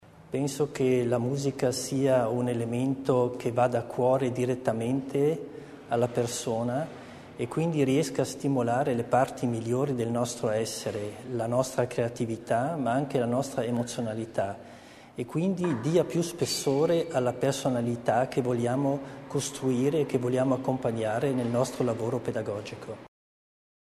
Landesrat Mussner über die Bedeutung der Zusammenarbeit
LPA - Um die Bedeutung der Musik in der Erziehung, die Zusammenarbeit zwischen Schule, Eltern und Bildungs- und Kultureinrichtungen sowie die Mehrsprachigkeit ging es heute, 9. September, beim Tag der ladinischen Schule in Brixen.